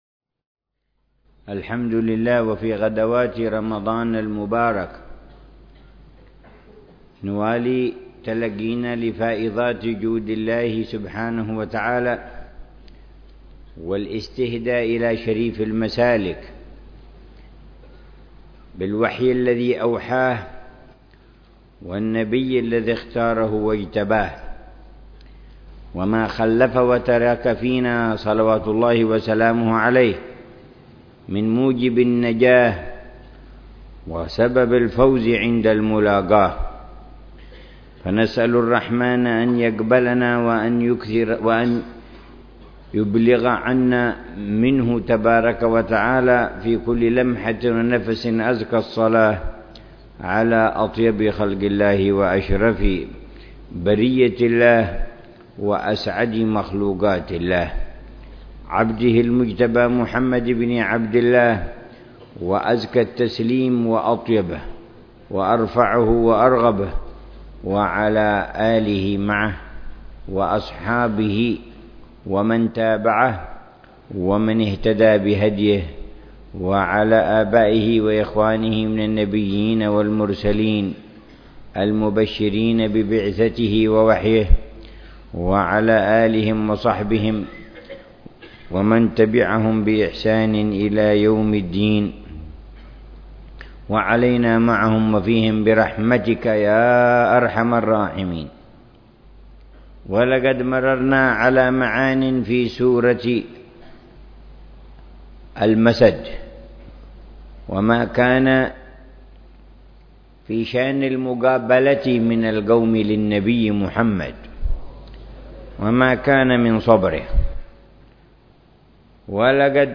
تفسير الحبيب عمر بن محمد بن حفيظ لسورة الفاتحة وقصار السور بدار المصطفى ضمن دروس الدروة الصيفية العشرين في شهر رمضان المبارك من العام 1435هـ.